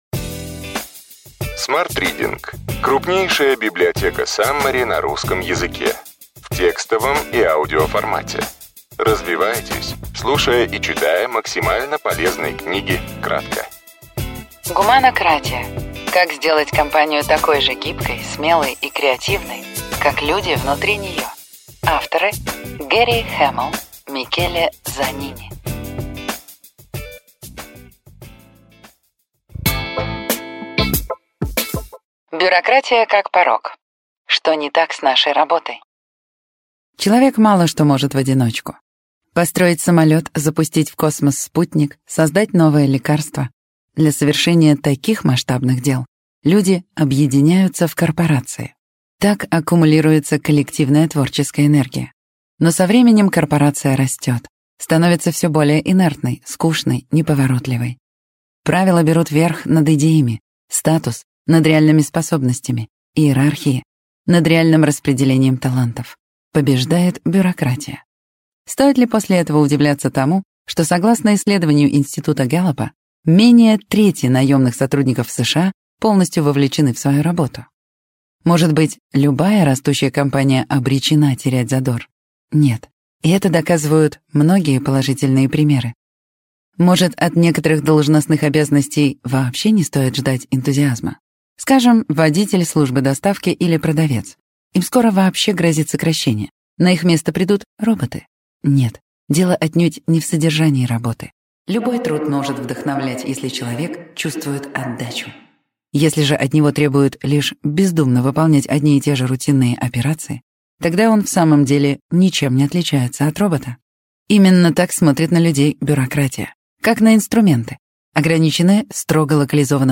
Аудиокнига Ключевые идеи книги: Гуманократия. Как сделать компанию такой же гибкой, смелой и креативной, как люди внутри нее.